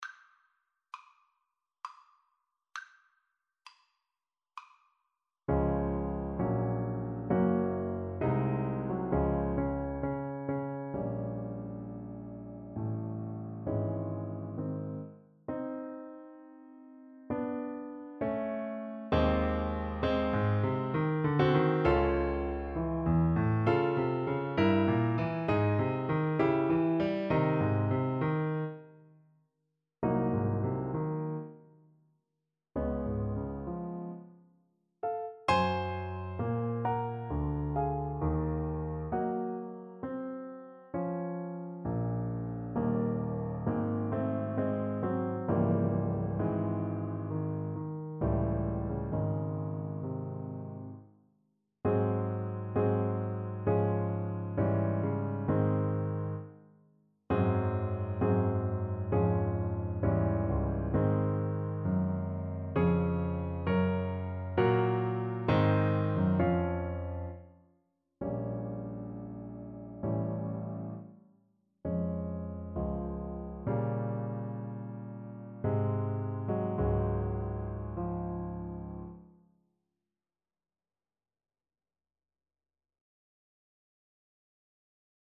Play (or use space bar on your keyboard) Pause Music Playalong - Piano Accompaniment Playalong Band Accompaniment not yet available reset tempo print settings full screen
Db major (Sounding Pitch) Eb major (Trumpet in Bb) (View more Db major Music for Trumpet )
3/4 (View more 3/4 Music)
=66 Andante sostenuto